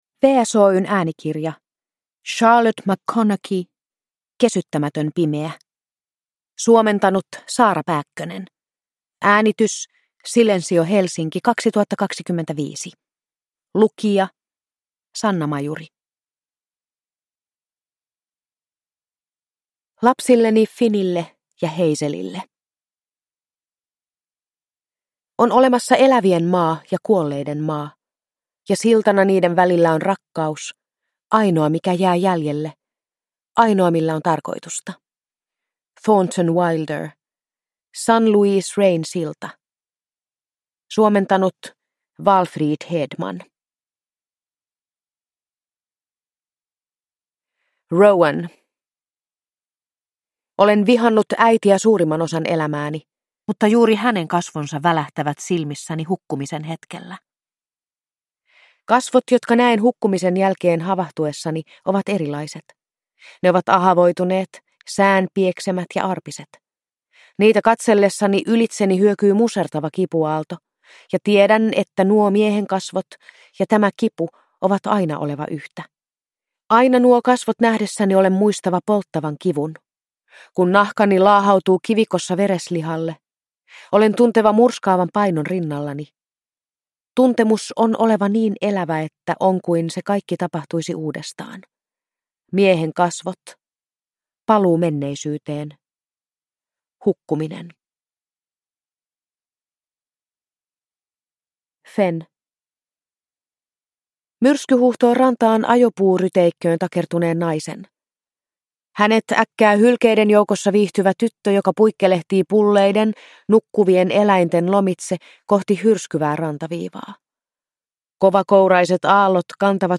Kesyttämätön pimeä – Ljudbok